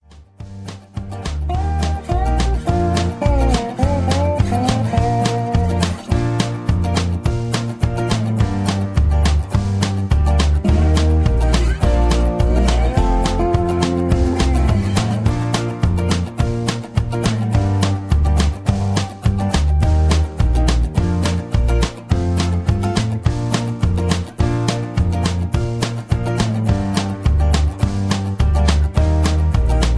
rock and roll, r and b, rock